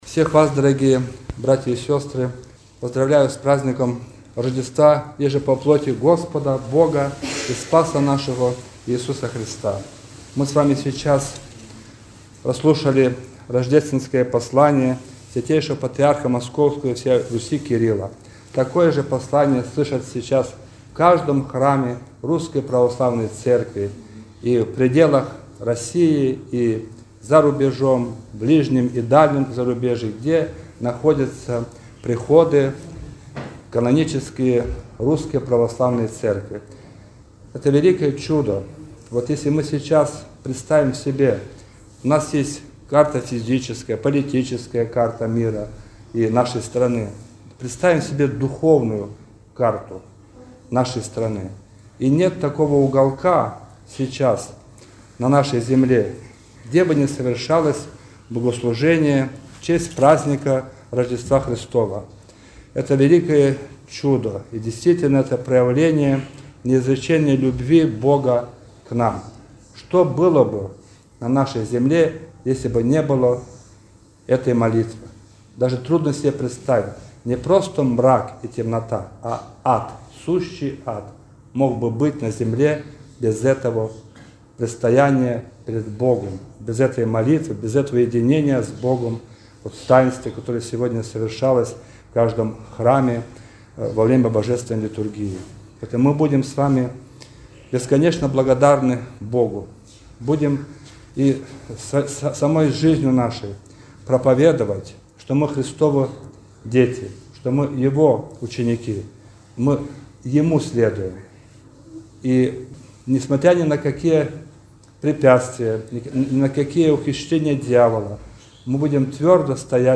Слово